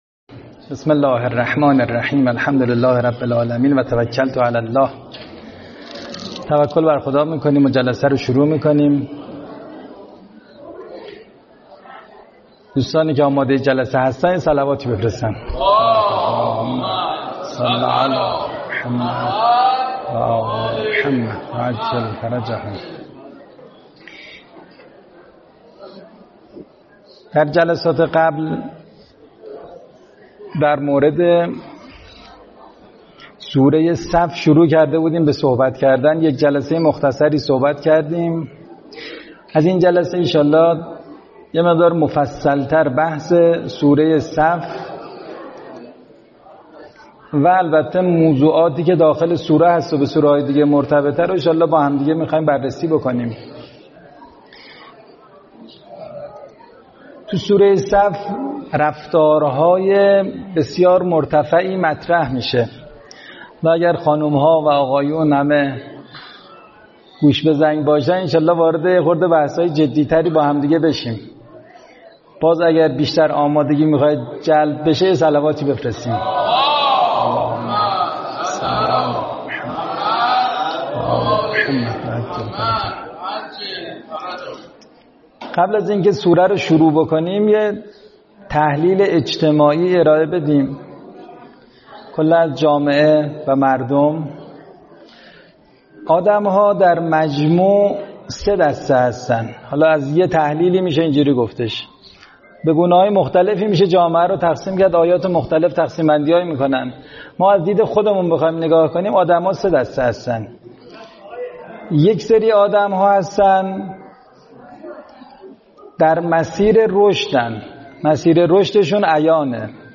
سخنرانی